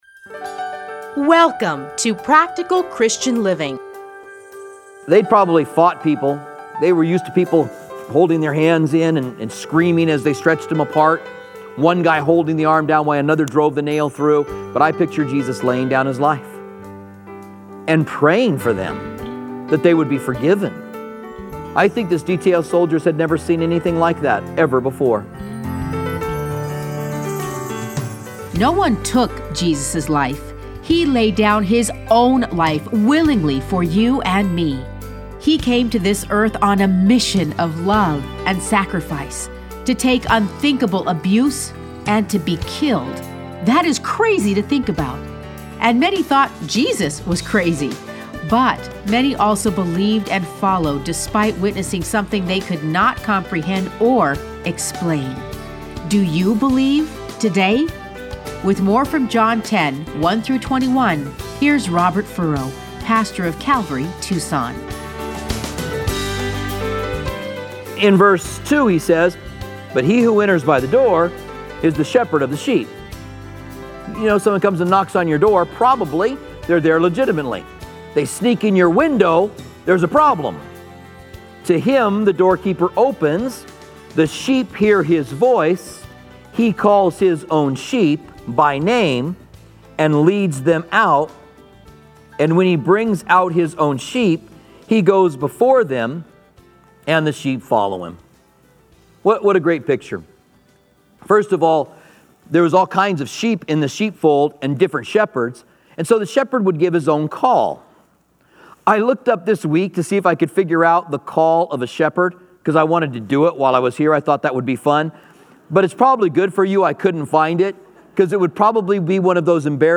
Listen to a teaching from John 9:13-41.